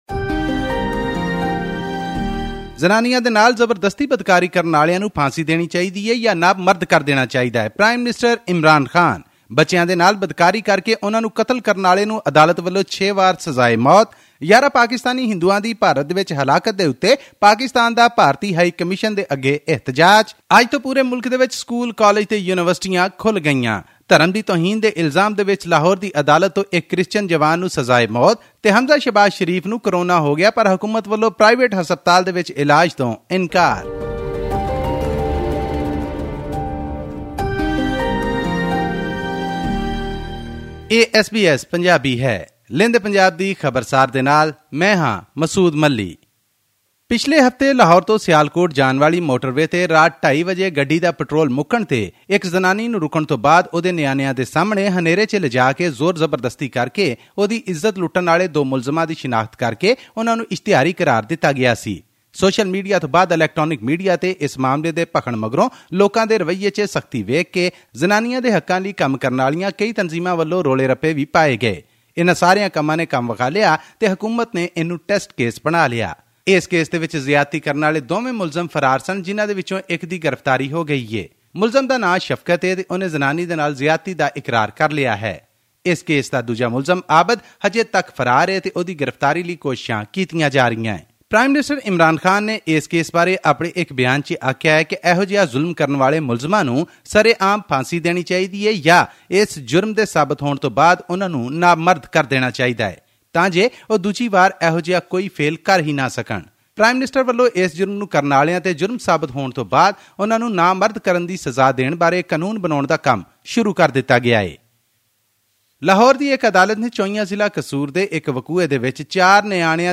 In our weekly news report from Pakistan’s Punjab province this week, hear more about the Lahore-Sialkot Motorway gangrape case that has stirred the country, the alleged death of 11 Pakistani Hindus in India, and an exemplary punishment for crimes against children.